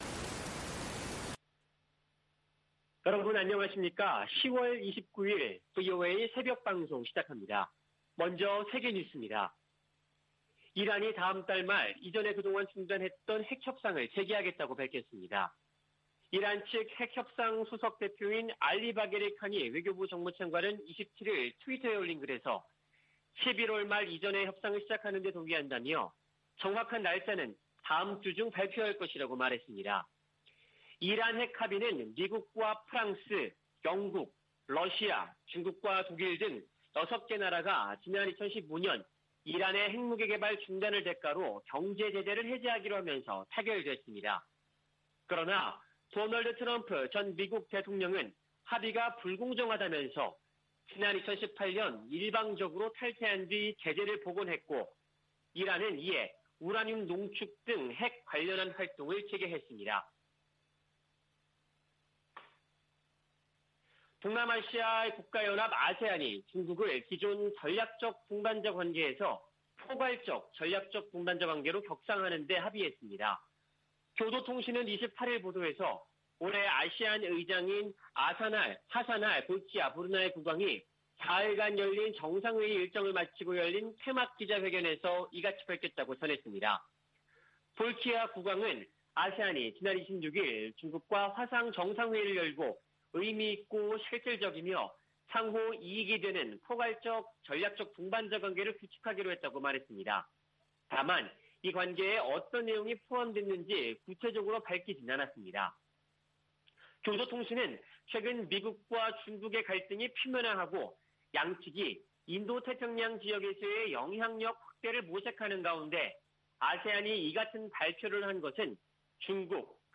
세계 뉴스와 함께 미국의 모든 것을 소개하는 '생방송 여기는 워싱턴입니다', 2021년 10월 29일 아침 방송입니다. '지구촌 오늘'에서는 조 바이든 미국 대통령이 타이완과 인권 문제 등을 놓고 중국을 비판한 소식, '아메리카 나우'에서는 불법체류자 체포 수가 10년 만에 최저로 떨어진 이야기 전해드립니다.